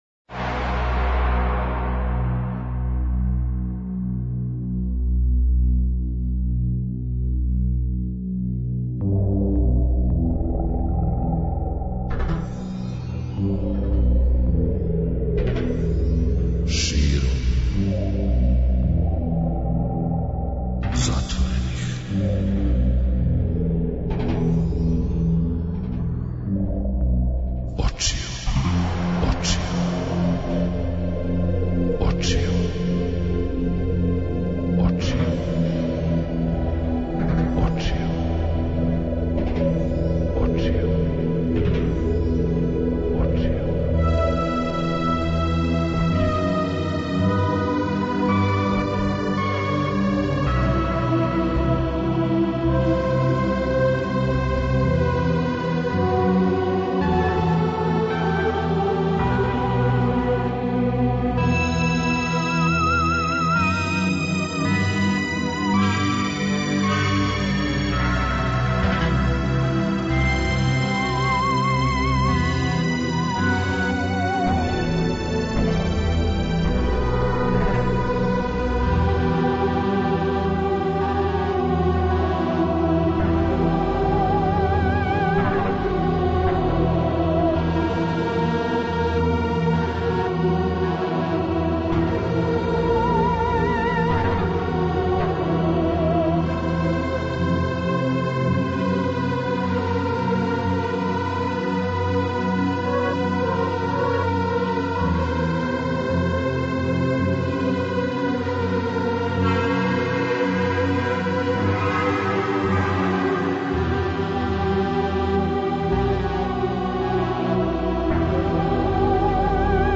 Ноћас говоримо о свеприсутној култури нарцизма, и пуштаћемо невероватне песме о нарцисима што не значи да уз њих треба да заиграте сами.